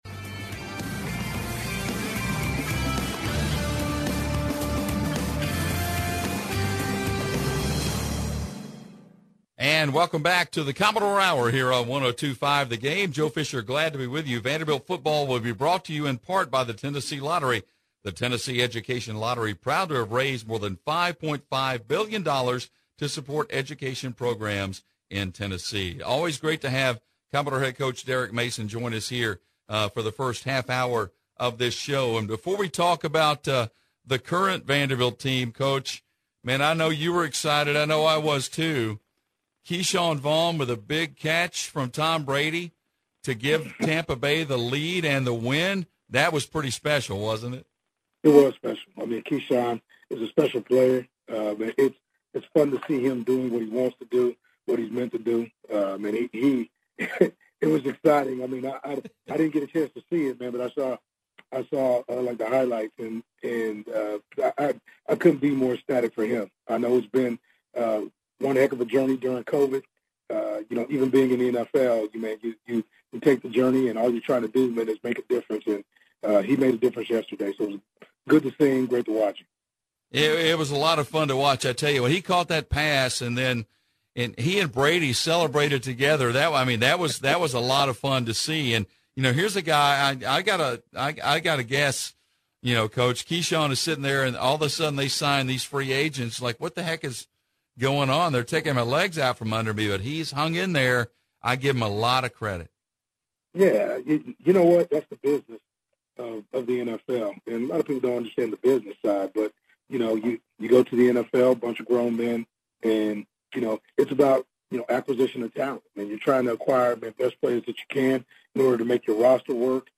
Vanderbilt football coach Derek Mason